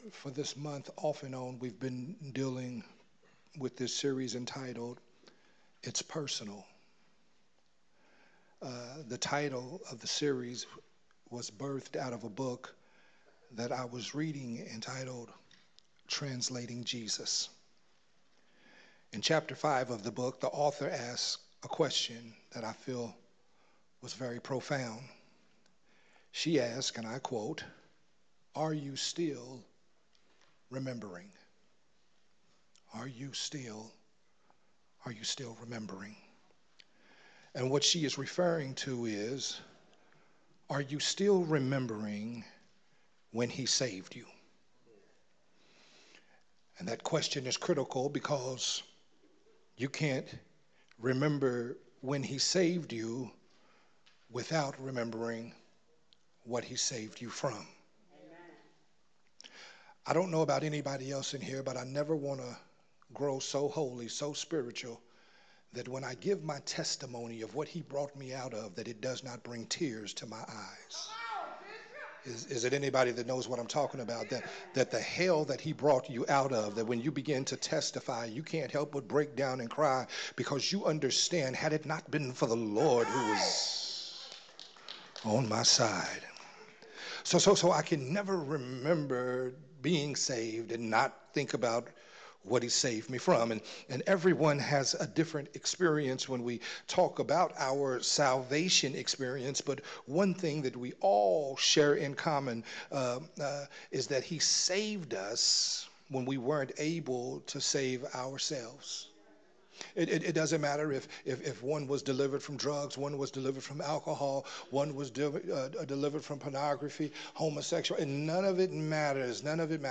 sermon
recorded at Unity Worship Center on October 27